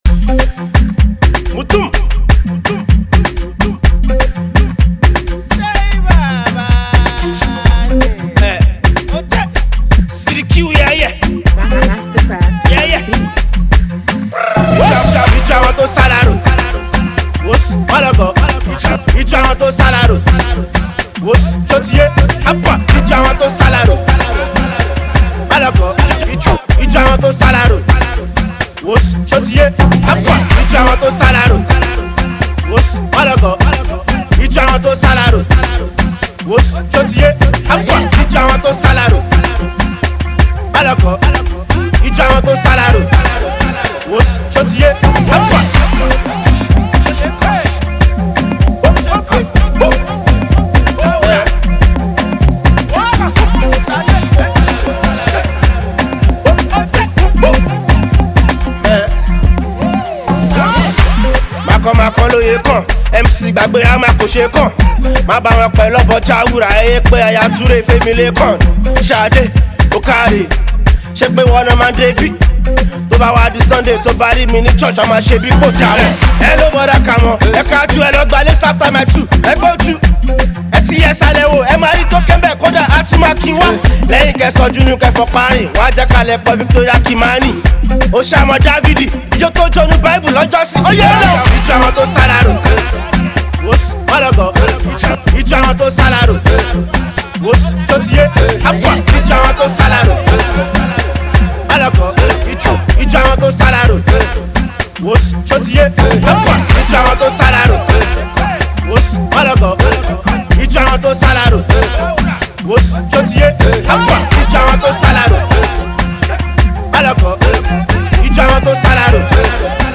Street Banger